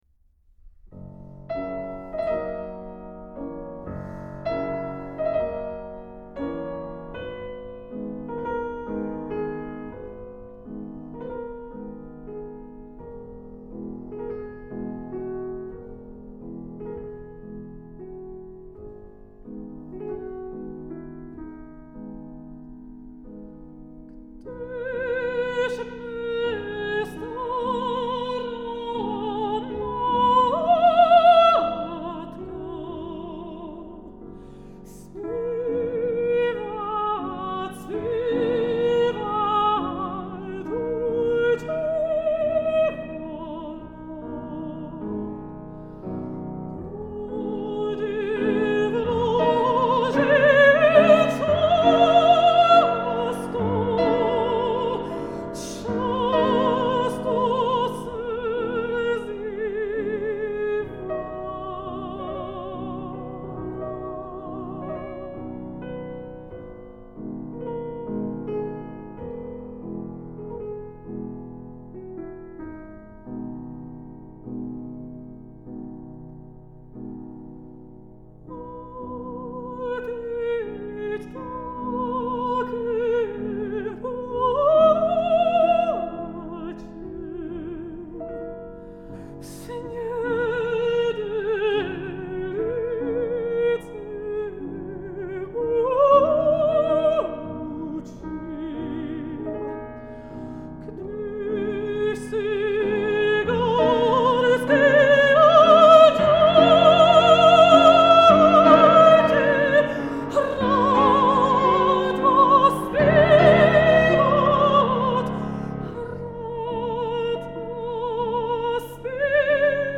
piano
a recital of songs by various Czech composers